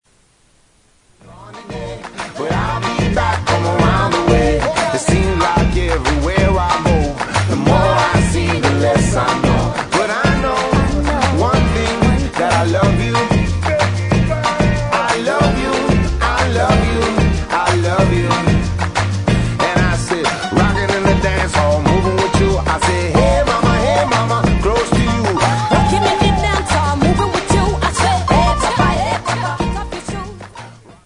• Hip-Hop Ringtones